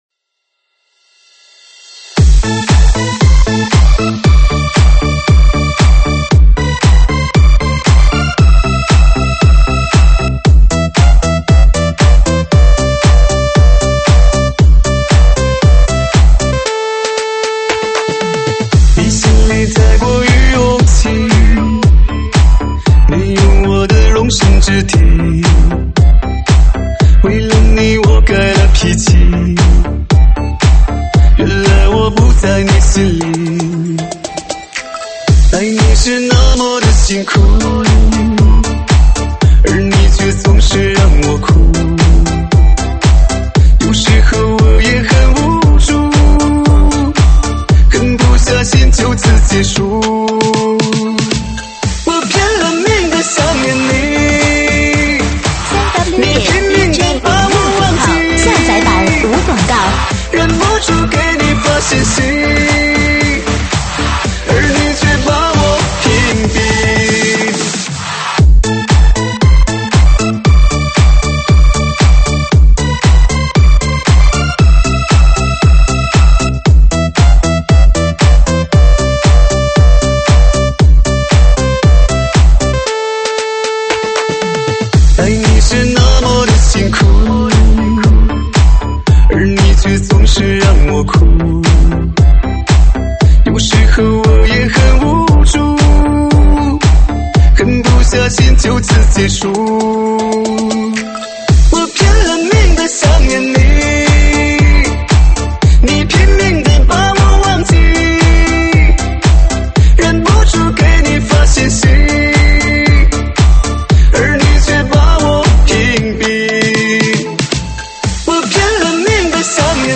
中文舞曲